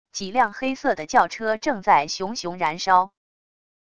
几辆黑色的轿车正在熊熊燃烧wav音频